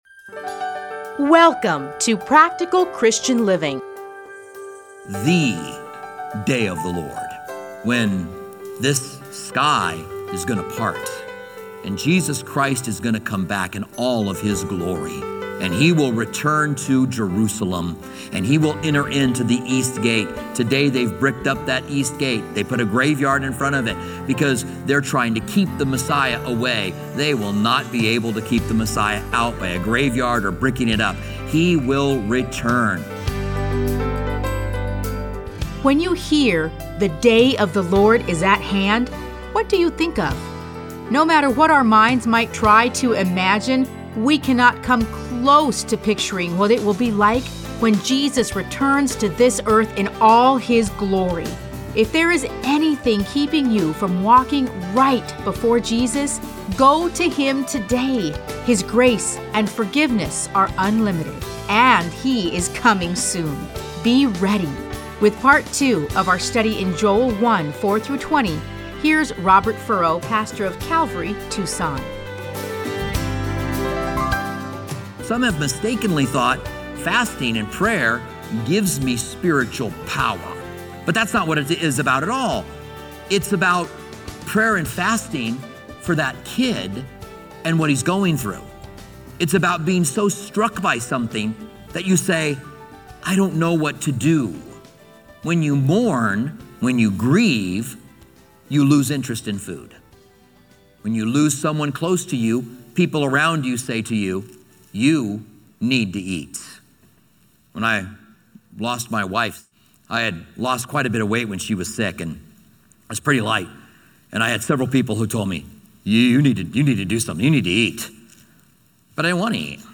Listen to a teaching from Joel 1:4-20.